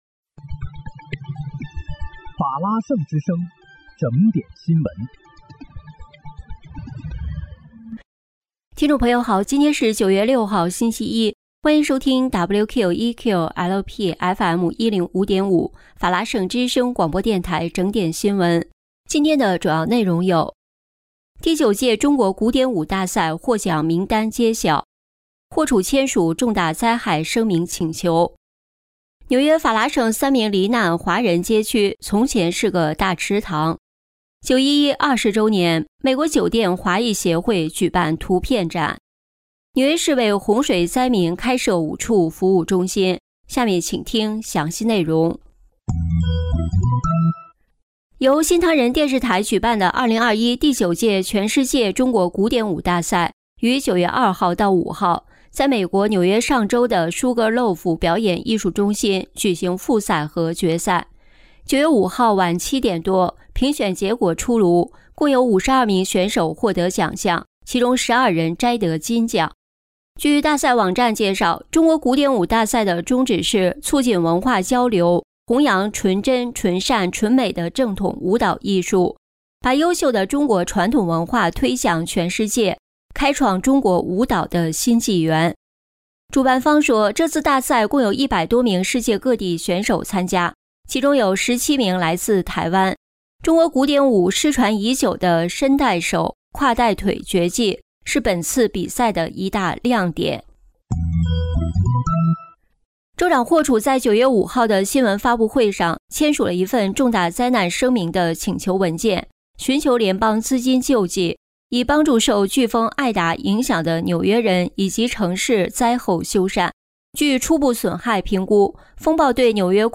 9月6日（星期一）纽约整点新闻